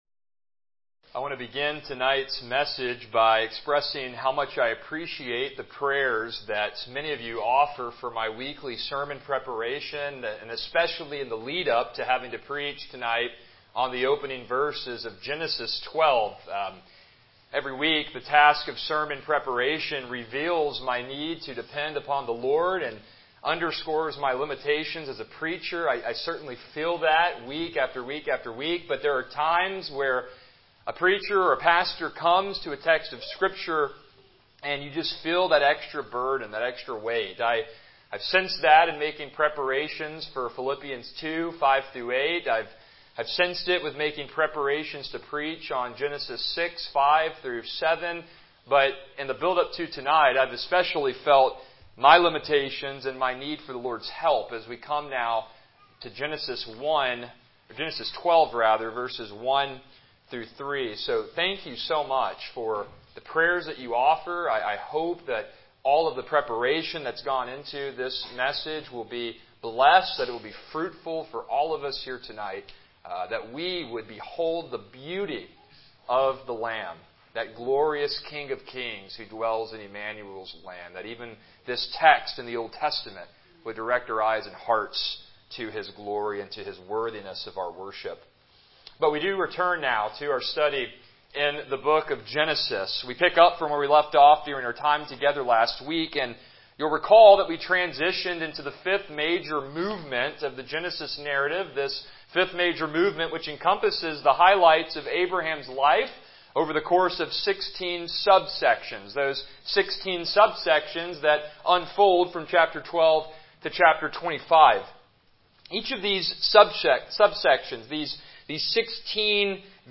Passage: Genesis 12:1-3 Service Type: Evening Worship